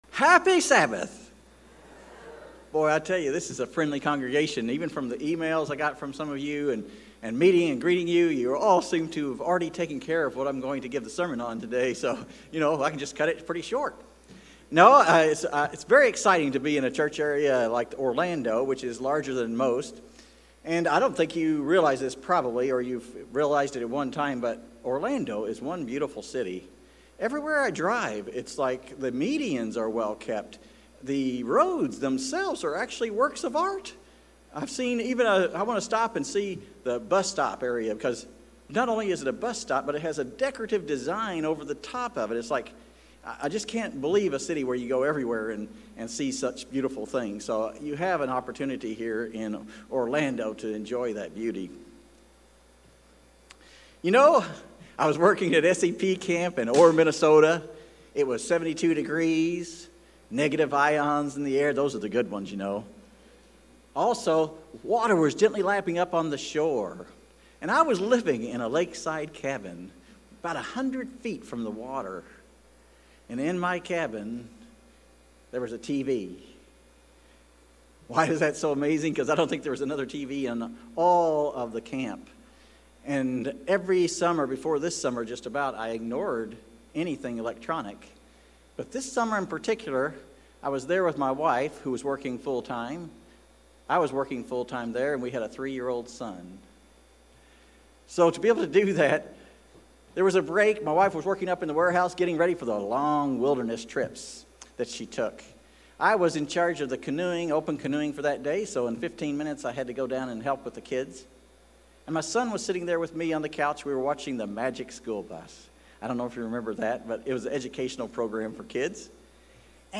This sermon is based on the second great commandment, love your neighbor.